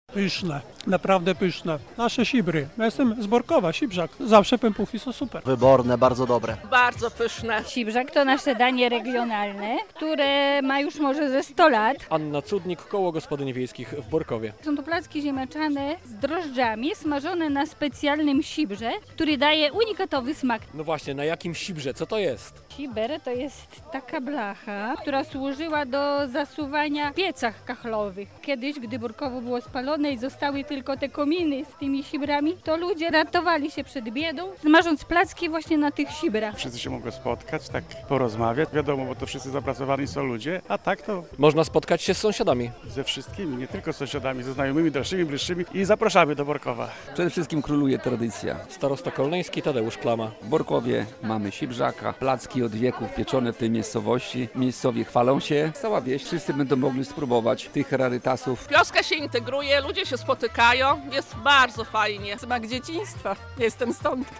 W miejscowości, w niedzielę (31.08) przy bibliotece trwa Dzień Sibrzaka.